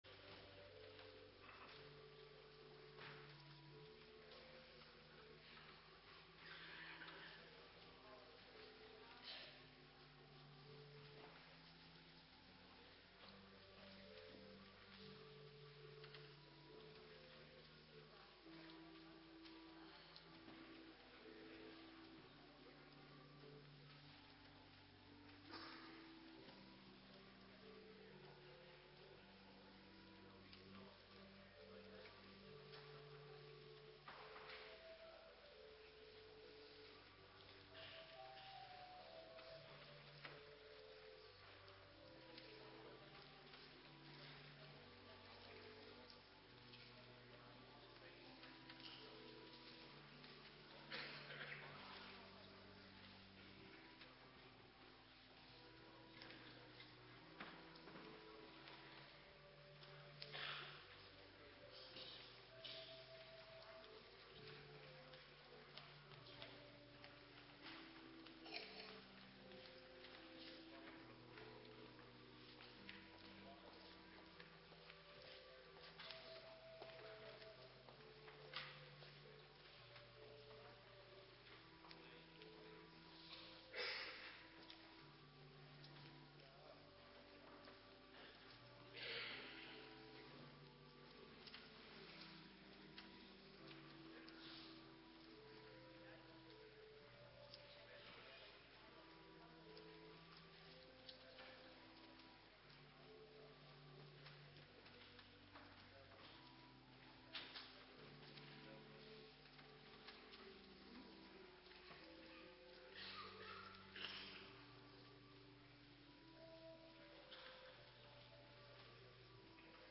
Bij uitzending is het geluid zwak als je audio luistert is het geluid beter wordt vd week verholpen ,is mis gegaan bij installeren. Uw begrip hiervoor Ps 74 . 2 en 18 Ps 125 . 4 Zach 12/13 . 1 Tekst Zach 12 . 1/4 Ps 83 . 1 . 3 en 9 Ps 147 . 1 Ps 102 . 7 en 12 Thema: Jeruzalem als lastige steen voor de volkeren